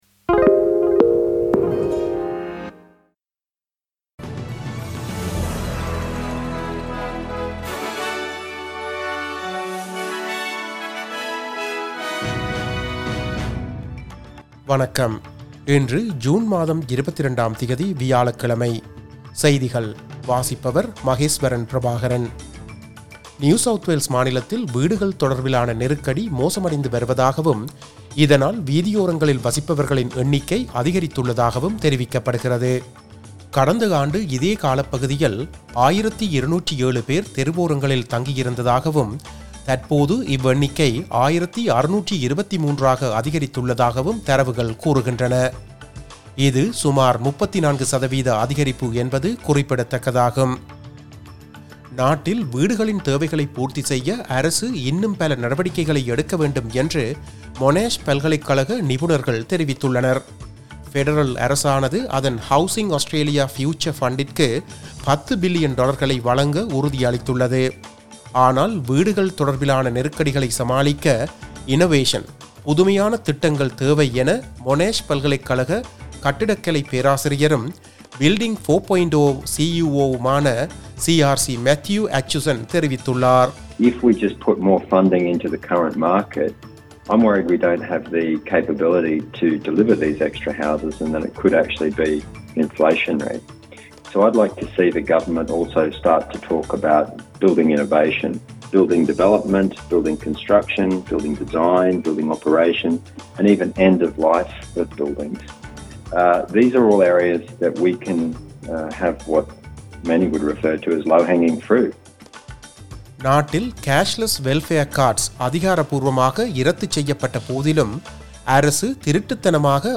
SBS தமிழ் ஒலிபரப்பின் இன்றைய (வியாழக்கிழமை 22/06/2023) ஆஸ்திரேலியா குறித்த செய்திகள்.